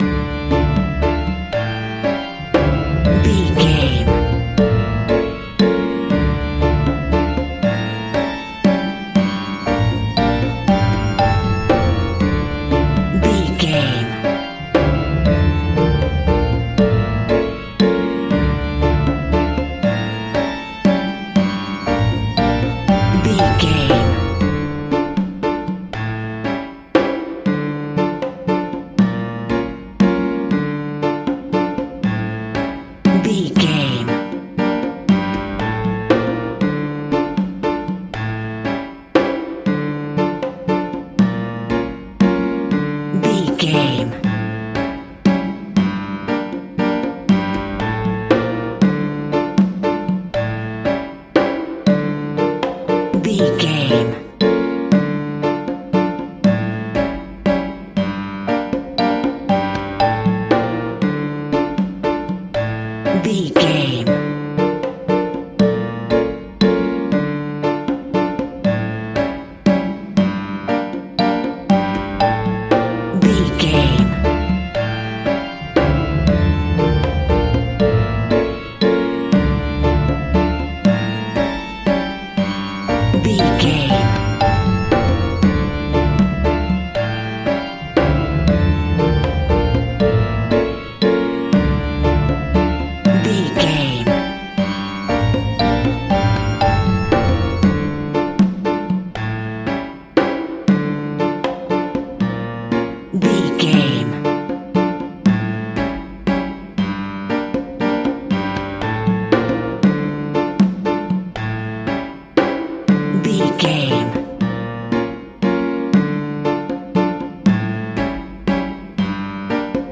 Fairground Death Music.
Aeolian/Minor
D
Slow
scary
ominous
dark
haunting
eerie
organ
percussion
piano
synth
ambience
pads